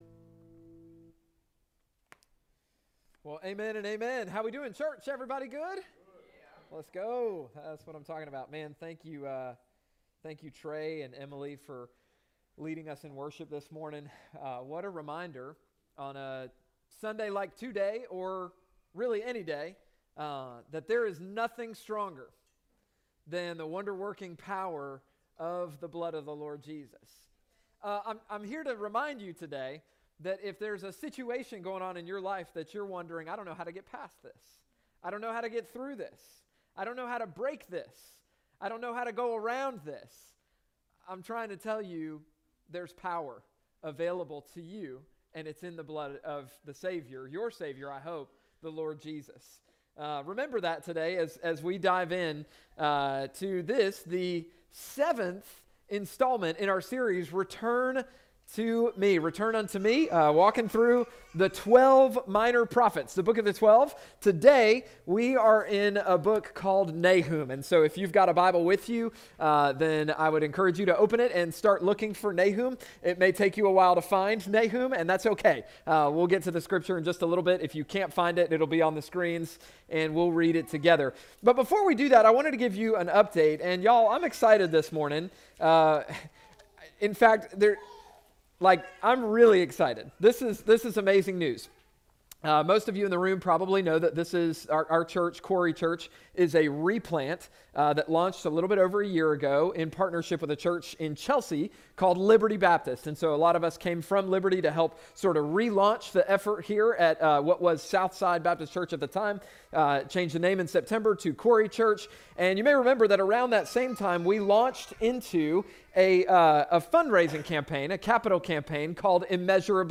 Contact Us New Worship Center Connect Ministries Events Watch QC Live Sermons Give 6.29.2025 - Return Unto Me // NAHUM June 29, 2025